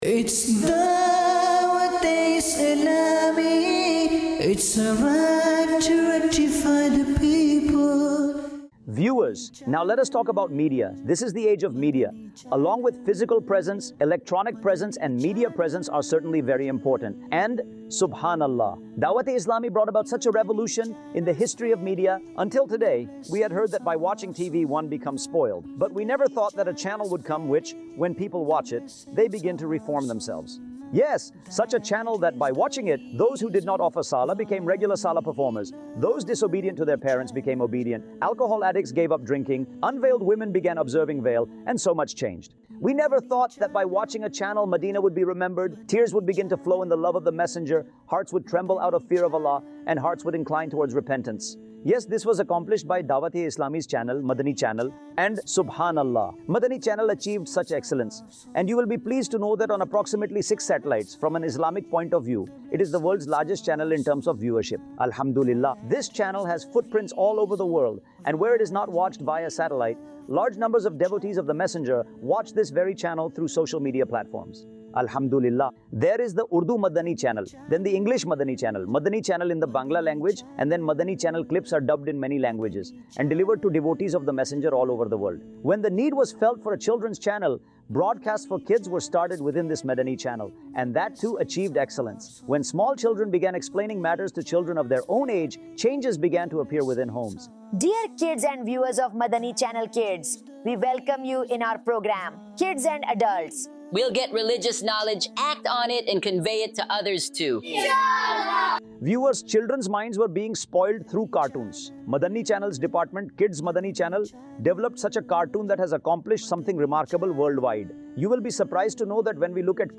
Social Media | Department of Dawateislami | Documentary 2026 | AI Generated Audio
سوشل میڈیا | شعبہِ دعوت اسلامی | ڈاکیومینٹری 2026 | اے آئی جنریٹڈ آڈیو